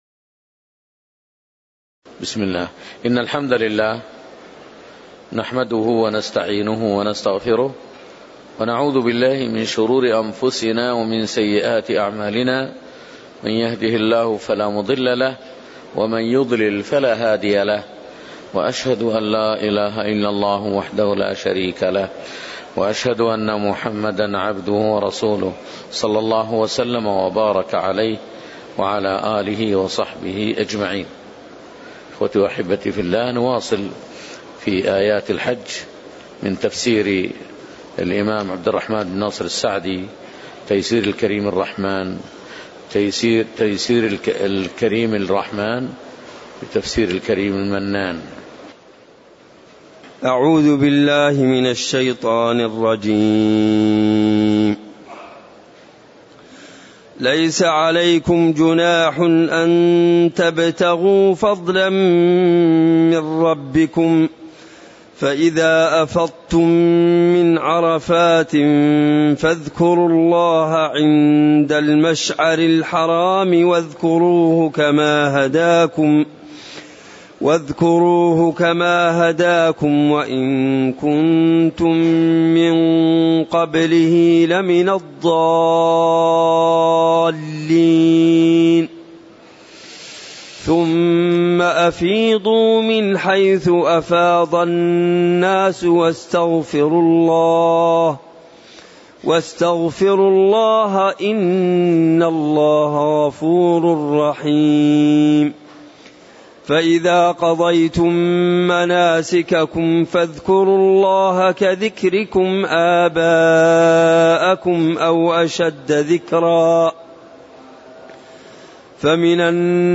تاريخ النشر ٢٧ ذو القعدة ١٤٣٨ هـ المكان: المسجد النبوي الشيخ